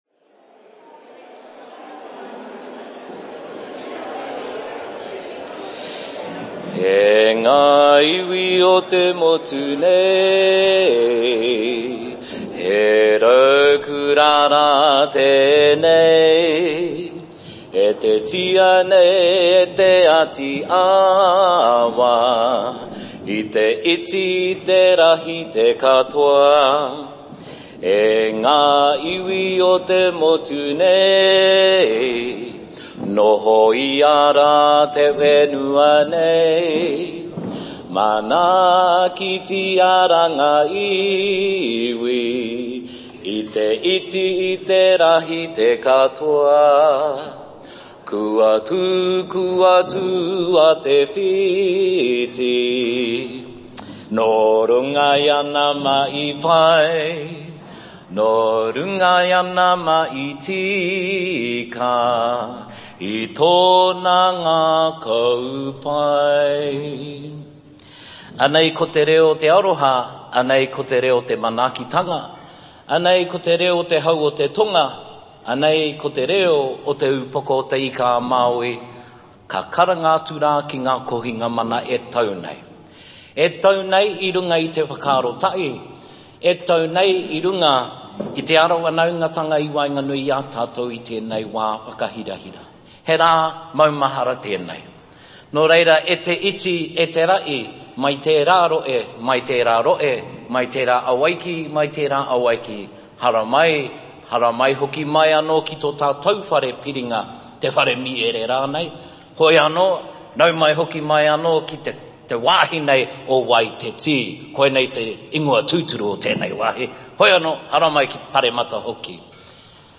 Audio from the second World AIDS Day Parliamentary Breakfast, held on 1 December 2025 in Wellington
Thanks to all of the participants at the event for allowing their speeches and kōrero to be recorded and shared.
Note during some of the presentations, a clinking sound can be quietly heard. This is because breakfast was being served at the time.